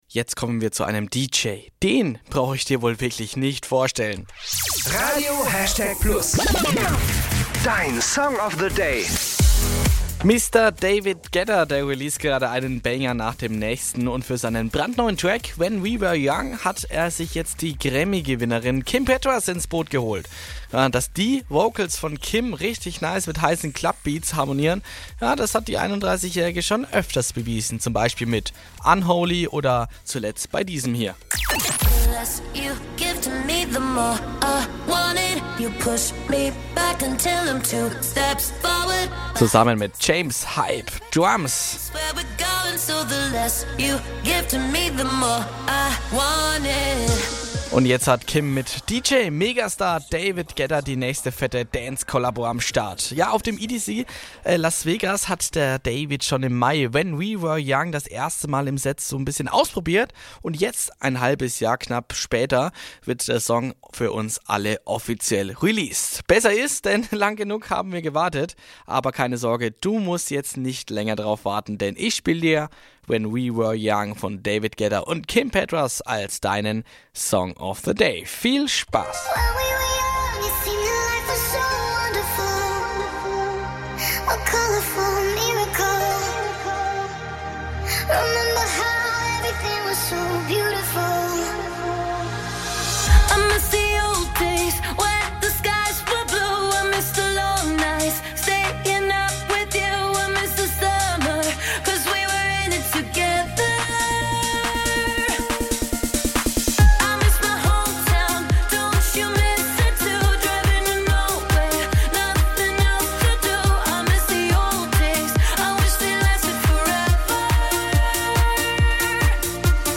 fette Dance Collabo